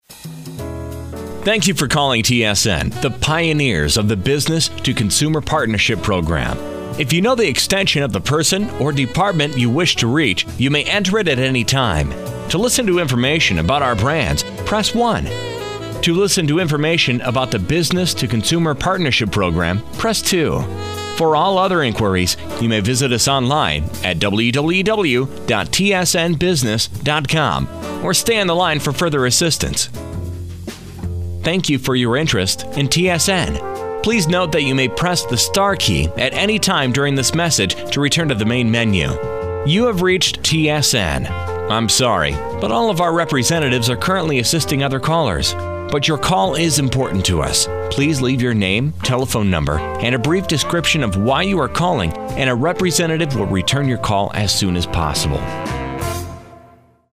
IVR客服系统